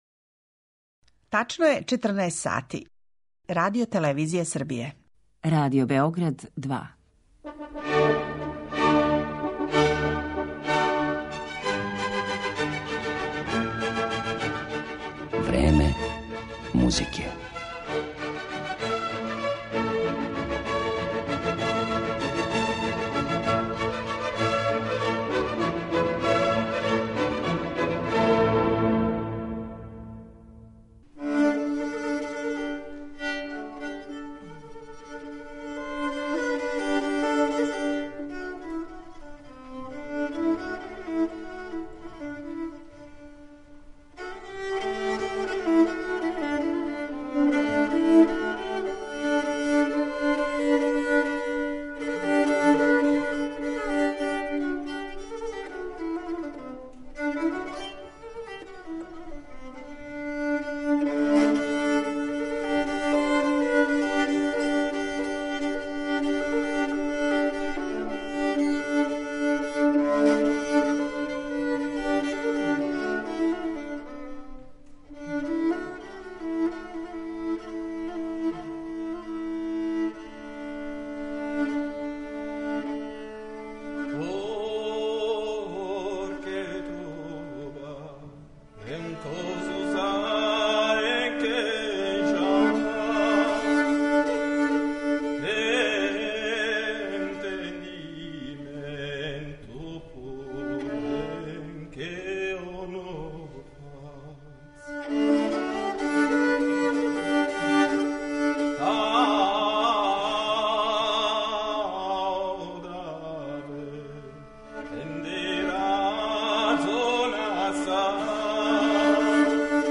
Њихов средњовековни музички свет биће обогаћен и кратким изводима из списа „Умеће праве љубави" њиховог савременика Андреаса Капелануса. Трубадурска култура рођена је почетком 12. века у Аквитанији, пределу данашње јужне Француске и северозападне Шпаније.